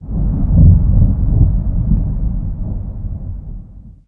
thunder19.ogg